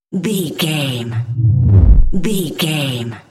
Deep whoosh pass by
Sound Effects
dark
intense
whoosh